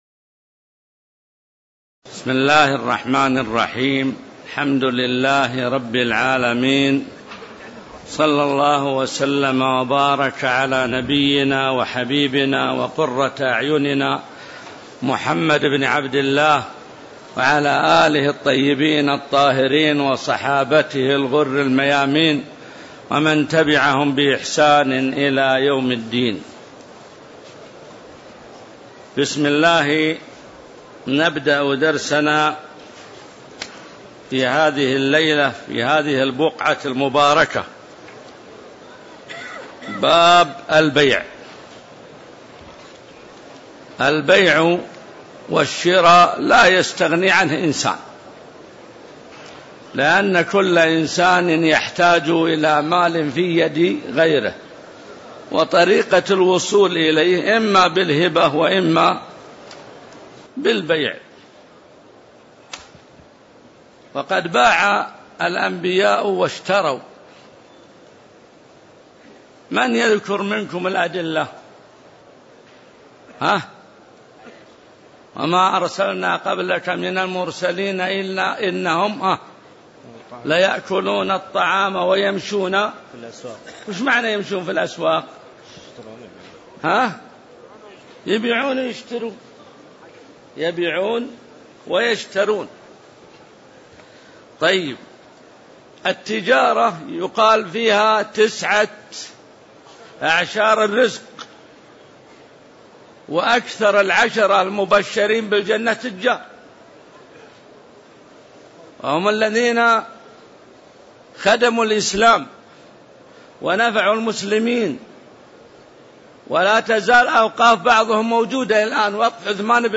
تاريخ النشر ٢٥ رجب ١٤٣٧ المكان: المسجد النبوي الشيخ: معالي الشيخ د. عبدالله بن محمد المطلق معالي الشيخ د. عبدالله بن محمد المطلق باب شروط البيع والبيوع المحرّمة